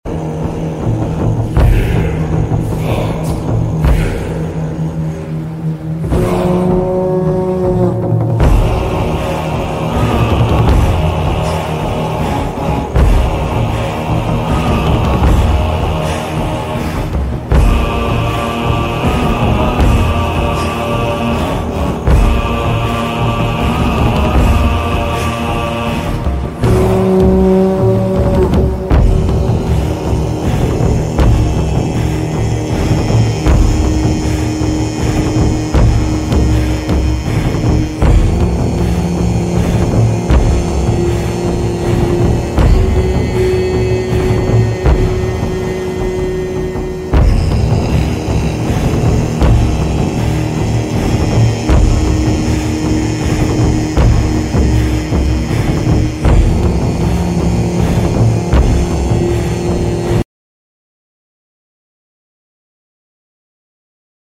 Dark Viking Music